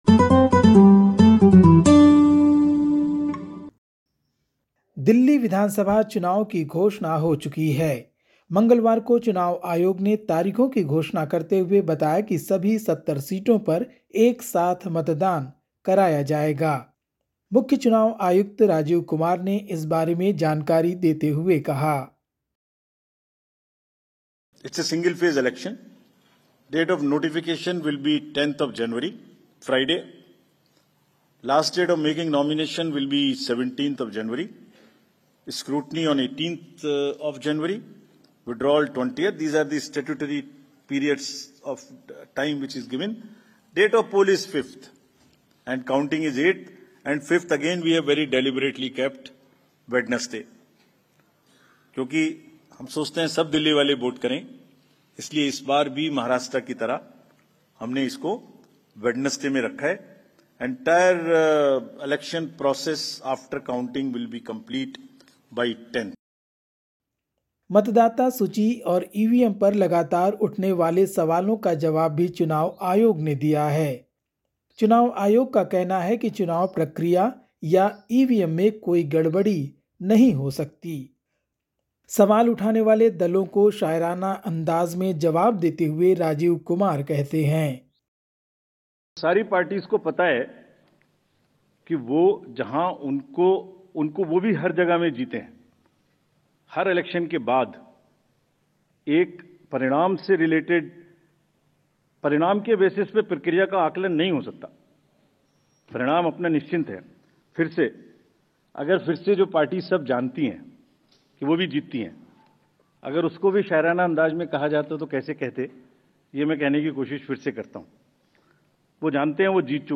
Listen to the latest SBS Hindi news from India. 08/01/2025